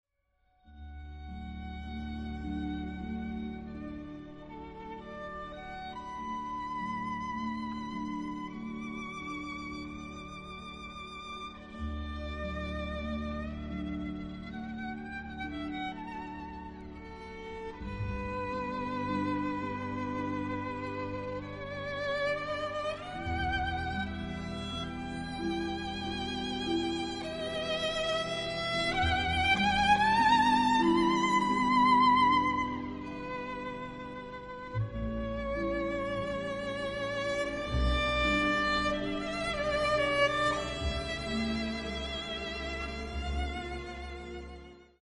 【所屬類別】 XRCD唱片　　古典音樂
for violin & orchestra